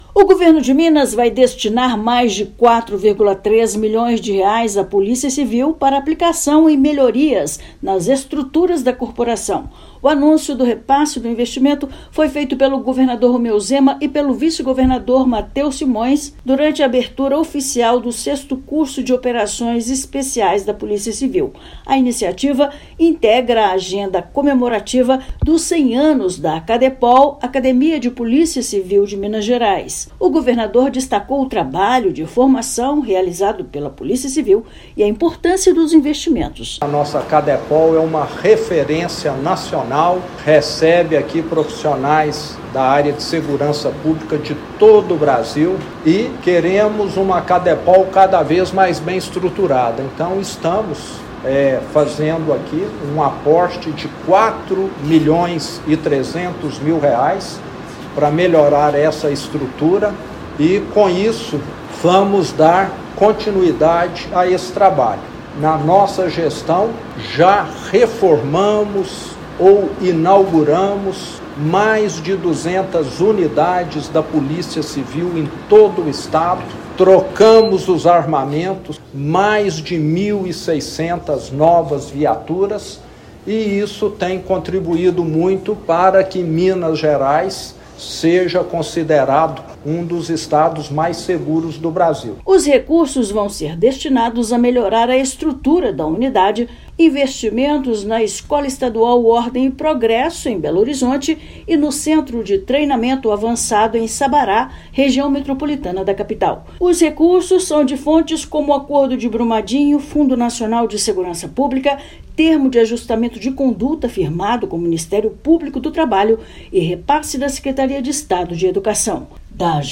Valor será aplicado na centenária Acadepol, no Centro de Treinamento Avançado de Sabará e na Escola Estadual Ordem e Progresso. Ouça matéria de rádio.